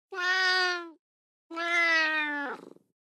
دانلود صدای گربه گرسنه از ساعد نیوز با لینک مستقیم و کیفیت بالا
جلوه های صوتی